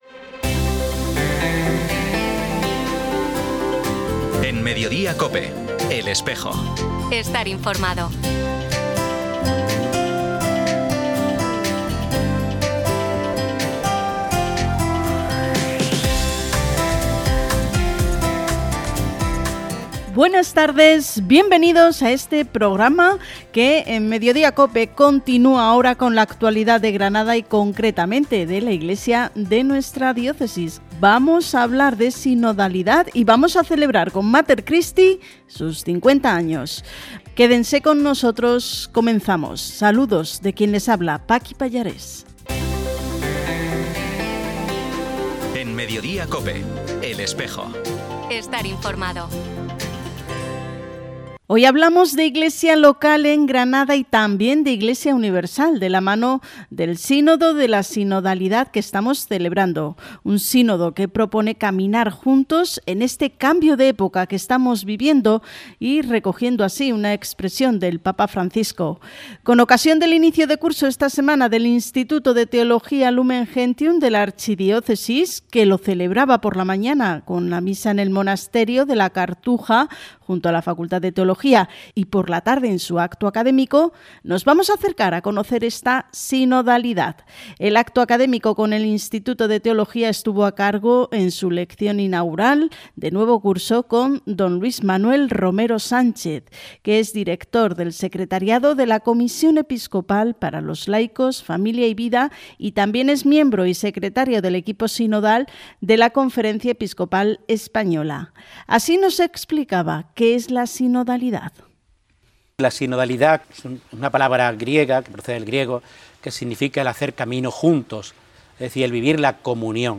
Disponible el programa emitido hoy en COPE Granada, el 19 de septiembre de 2025.